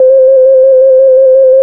WOBBLER 2.wav